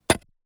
suppression de la reverb sur les sfx de pioche
pickaxe_4.wav